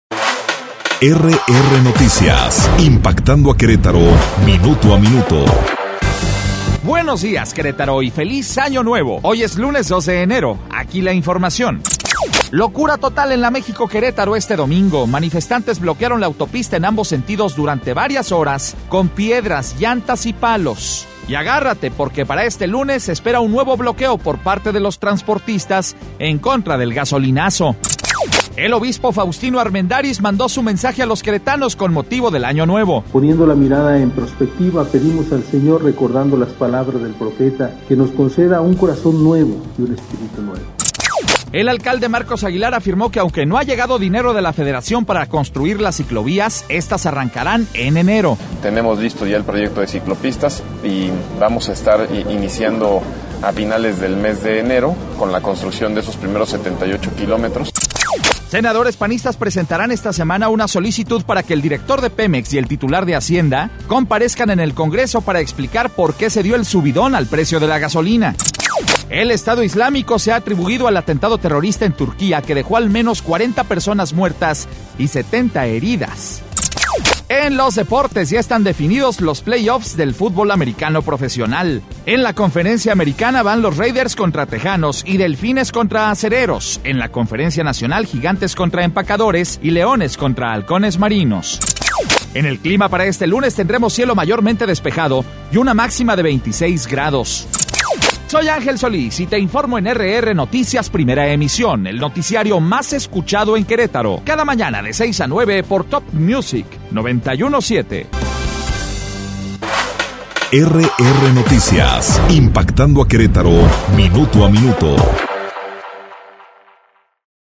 Resumen Informativo 2 de enero - RR Noticias